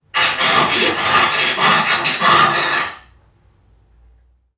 NPC_Creatures_Vocalisations_Robothead [73].wav